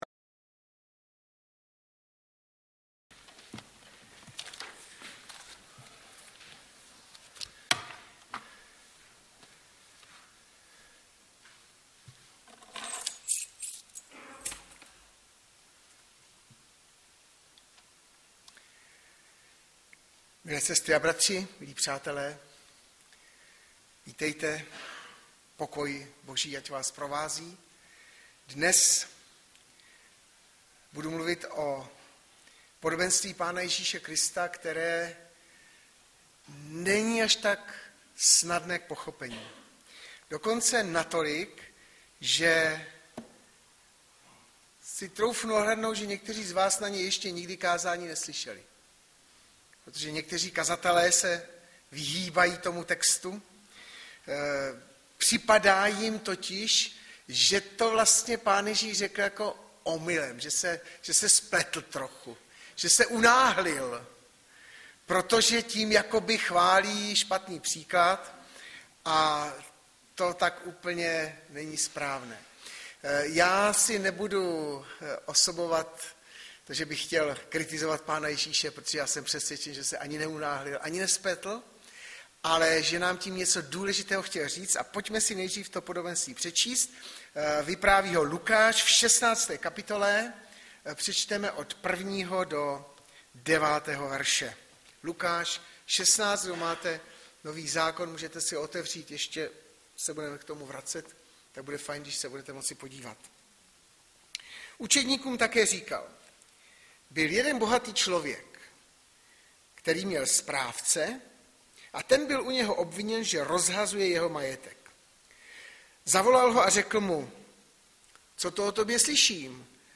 Audiozáznam kázání si můžete také uložit do PC na tomto odkazu.